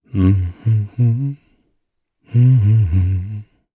Clause_ice-Vox_Hum_jp.wav